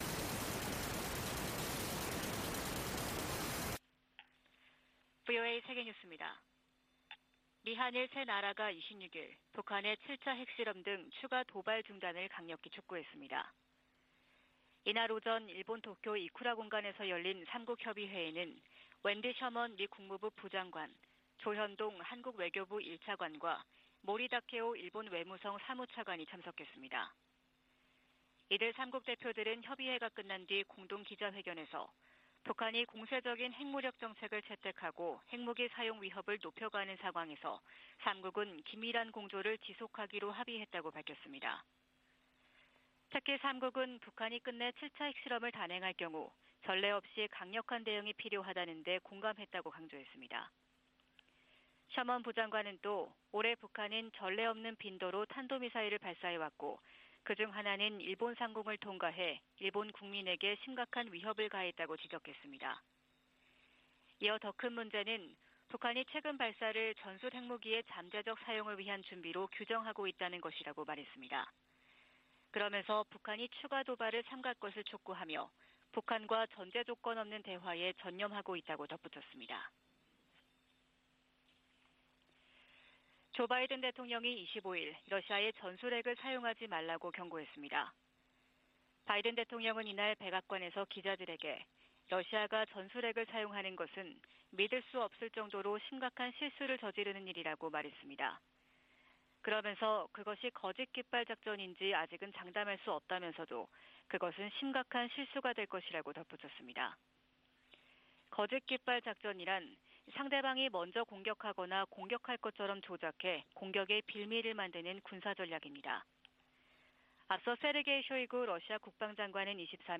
VOA 한국어 '출발 뉴스 쇼', 2022년 10월 27일 방송입니다. 미국·한국·일본의 외교차관이 26일 도쿄에서 협의회를 열고 핵실험 등 북한의 추가 도발 중단을 강력히 촉구했습니다. 미 국무부는 북한이 7차 핵실험을 강행할 경우 대가를 치를 것이라고 경고한 사실을 다시 강조했습니다. 미 국방부는 한반도 전술핵 재배치와 관련한 질문에 강력한 억지력 보장을 위해 한국·일본 등 동맹과 긴밀히 협력할 것이라고 밝혔습니다.